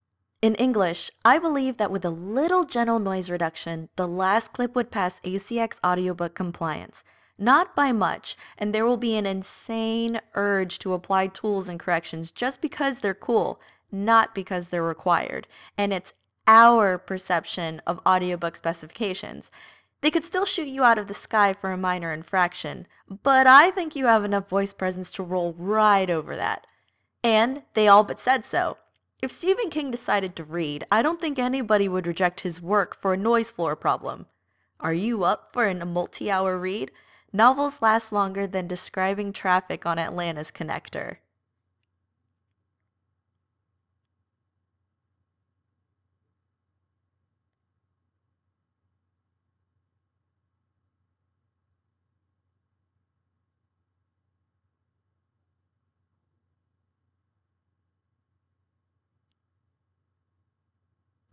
That room tone segment is insanely handy.
I like what you did, the echo that I hear on my end is almost non existent! :3